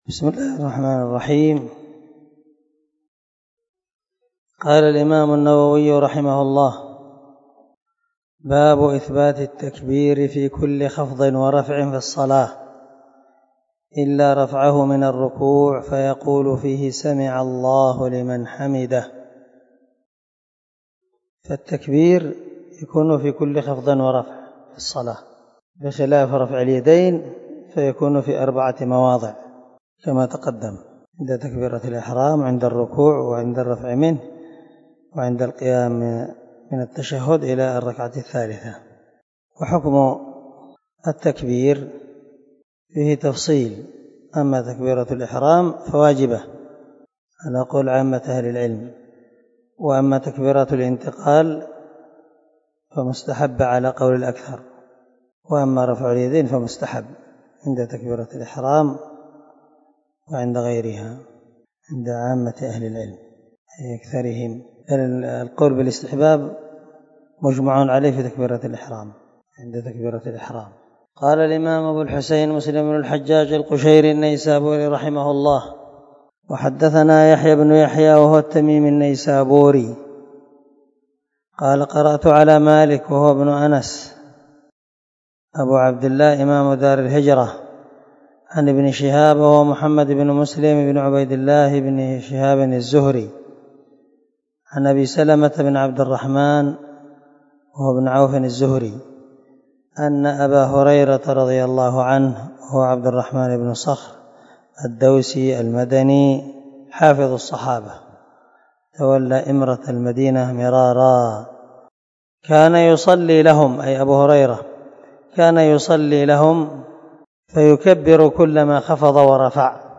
سلسلة_الدروس_العلمية
دار الحديث- المَحاوِلة- الصبيحة